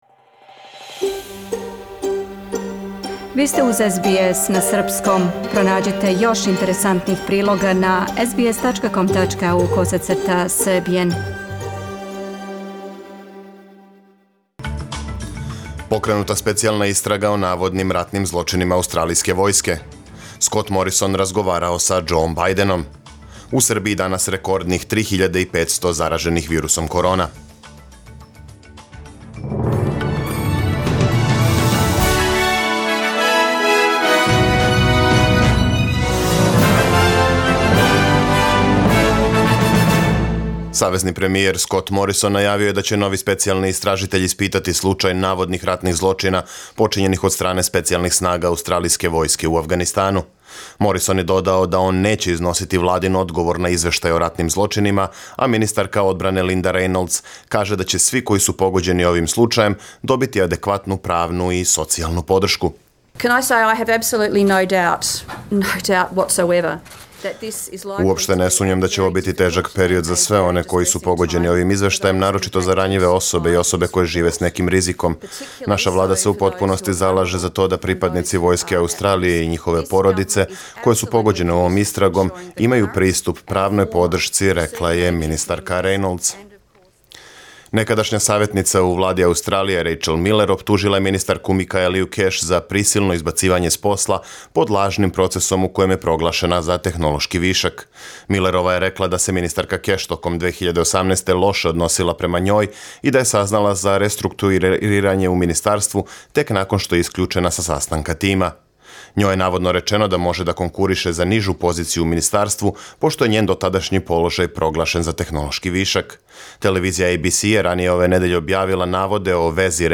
Преглед вести за 12. новембар 2020. године